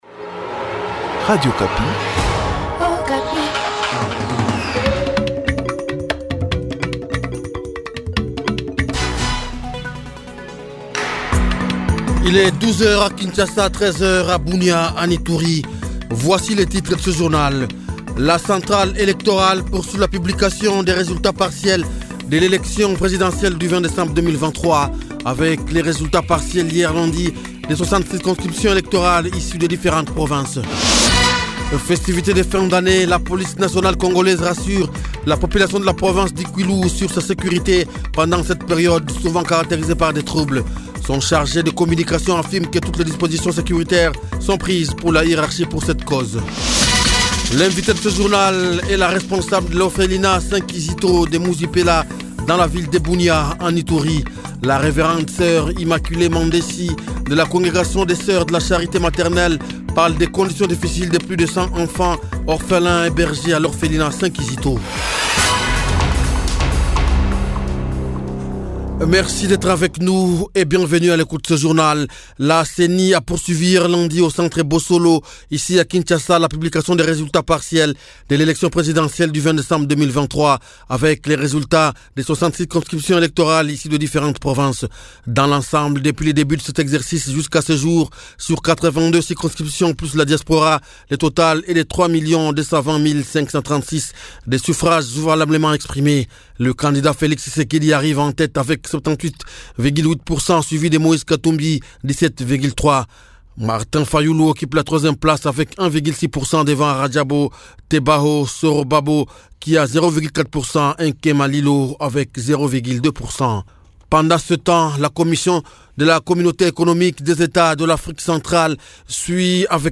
Journal Francais